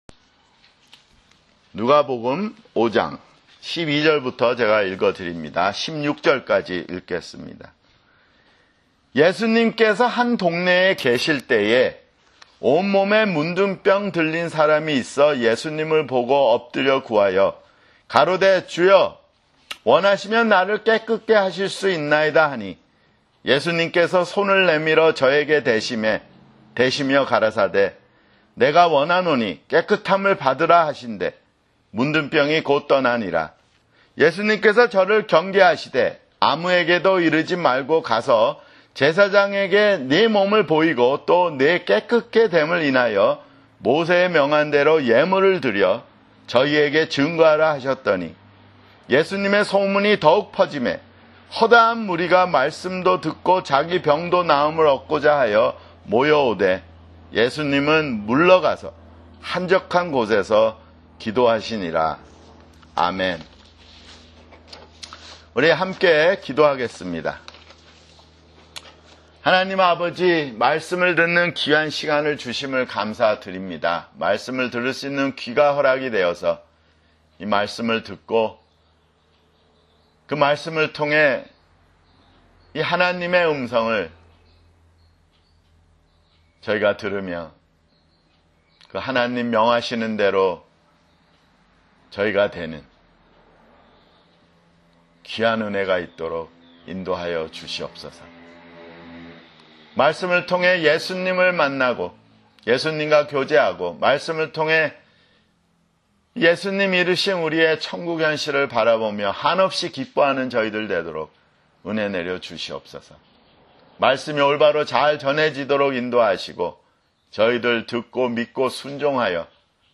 [주일설교] 누가복음 (33)